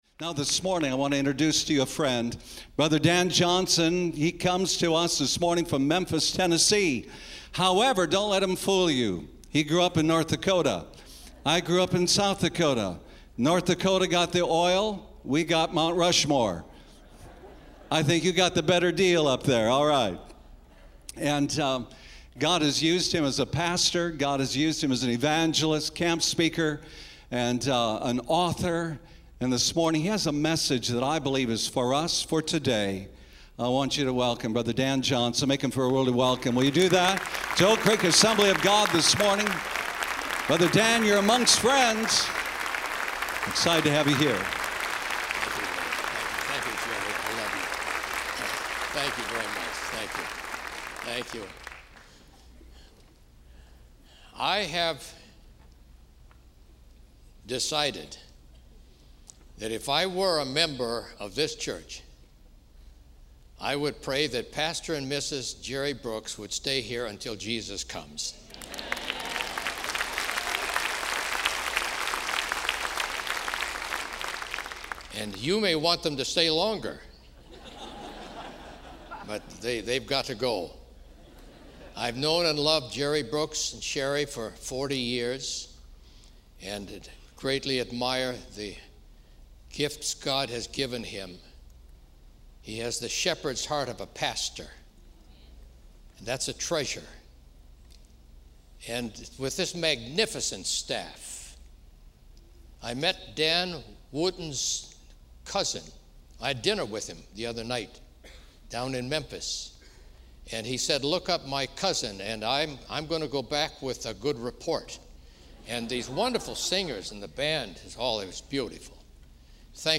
This entry was posted on Tuesday, November 10th, 2015 at 5:54 pm and is filed under Sermons.